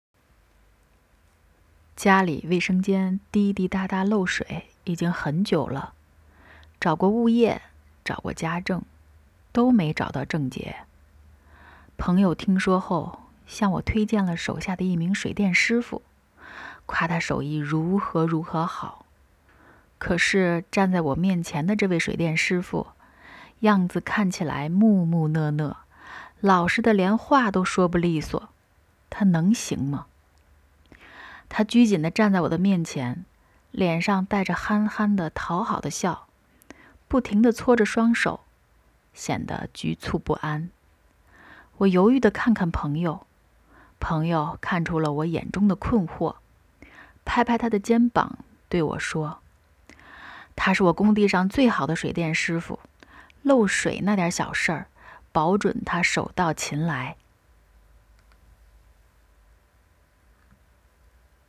讲述类文稿.mp3